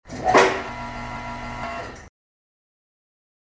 lift.mp3